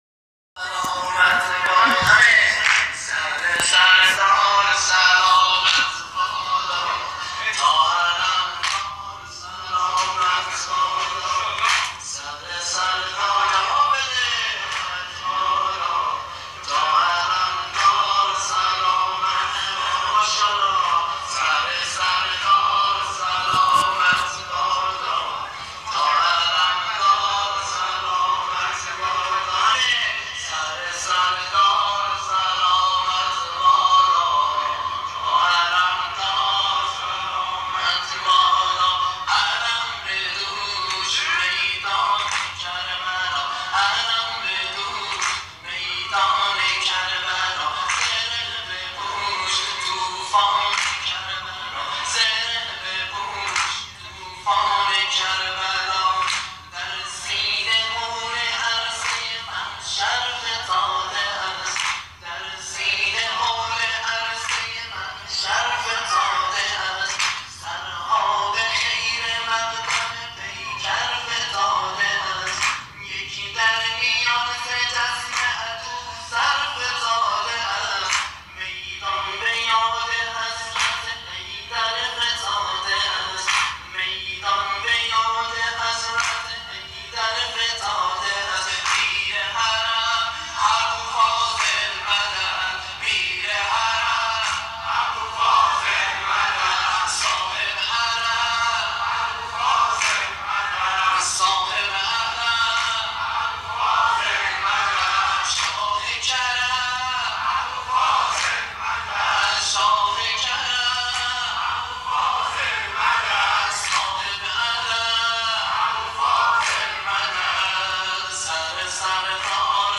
نوحه سر سردار سلامت بادا متاسفانه مرورگر شما، قابیلت پخش فایل های صوتی تصویری را در قالب HTML5 دارا نمی باشد.